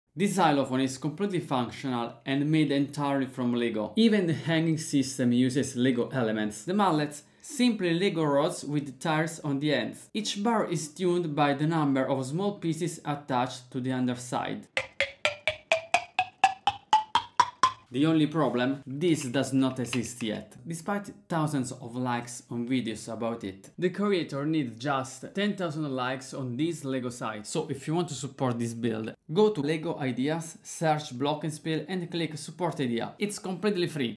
A working xylophone made entirely sound effects free download
Yes — and it sounds amazing!
Every part is made from LEGO, from the bars to the mallets — and it actually plays real music!